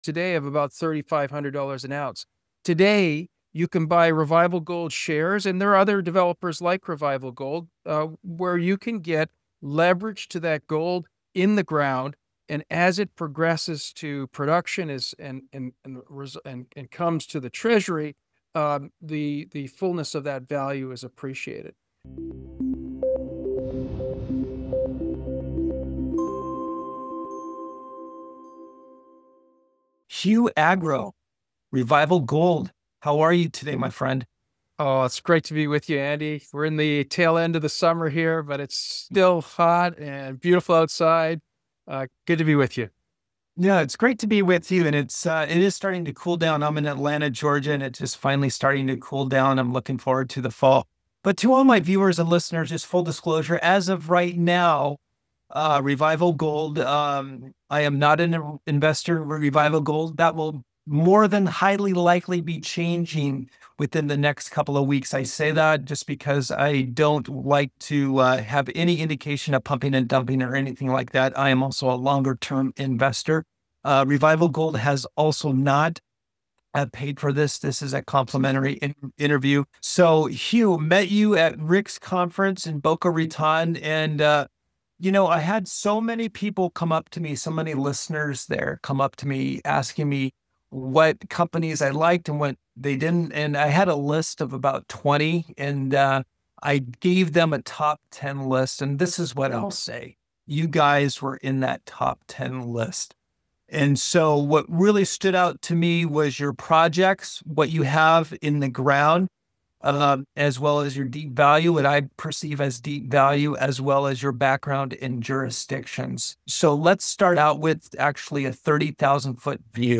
Revival Gold's $1.2 Billion Potential An Interview